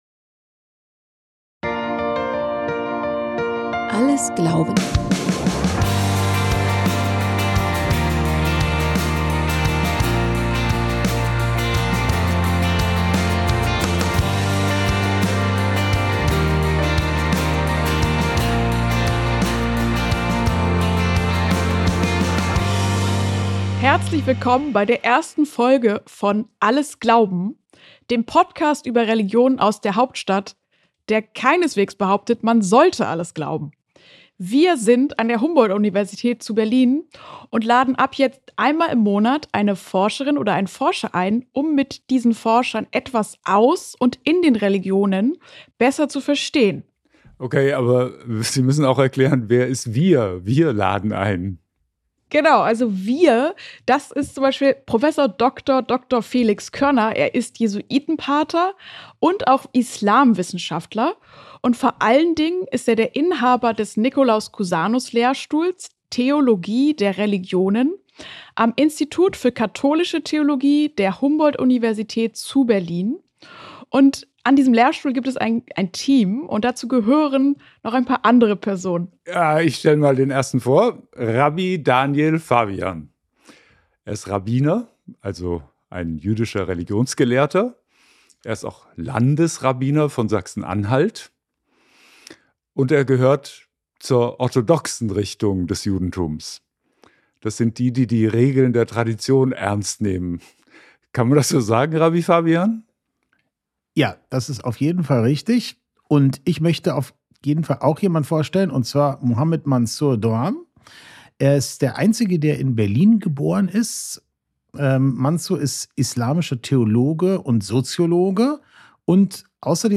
Aus jüdischer, christlicher und muslimischer Sicht geht es um die Entstehung von Religionen, ihre Bedeutung im Alltag und ihren Platz in einer pluralen Gesellschaft. Ein Gespräch über Unterschiede, Gemeinsamkeiten und das Verbindende im Glauben.